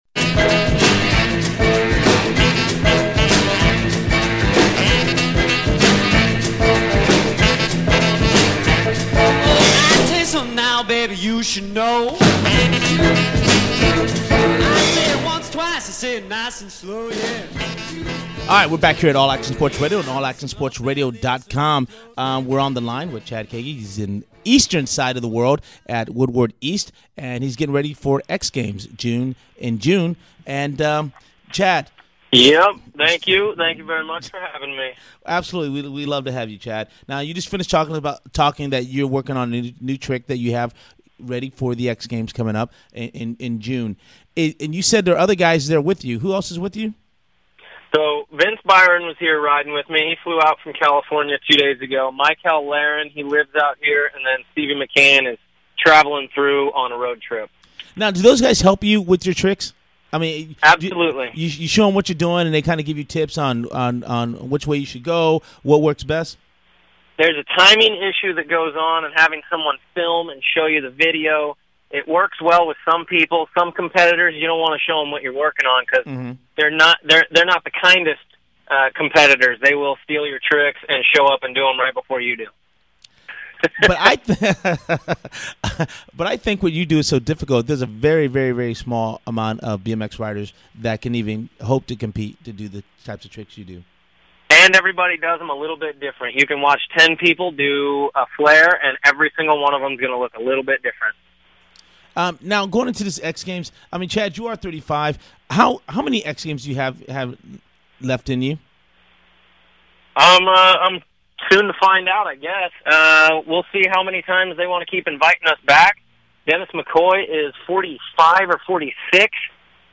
All Action Sports Radio Athlete Interview